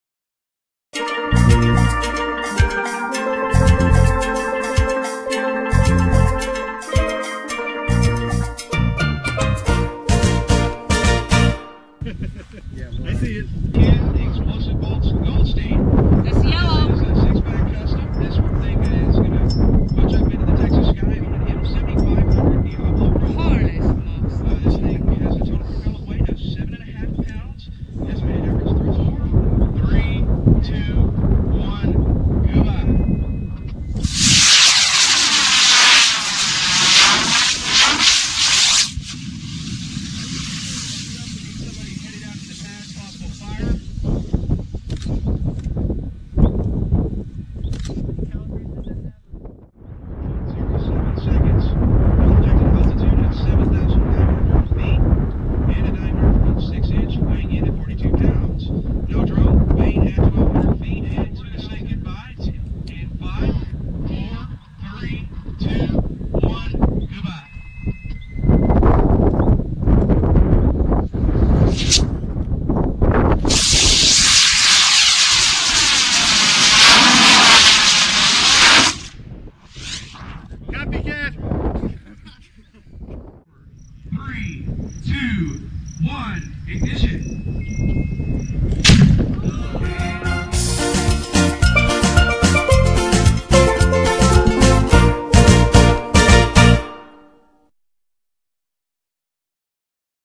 See some of the cool video taken at LDRS: